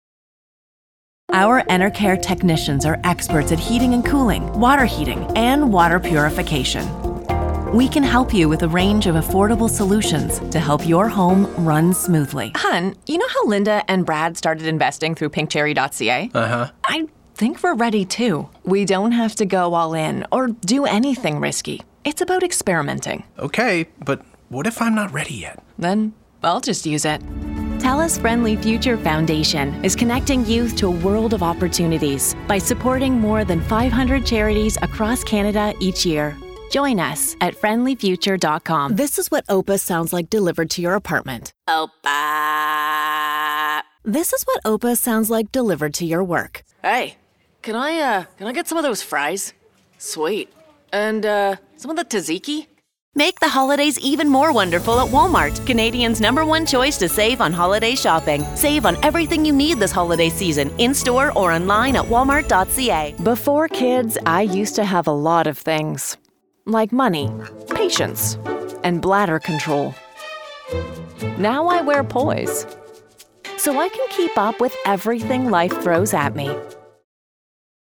Authentic, warm and relatable.
Commercial Demo
Canadian, General American and Na'vi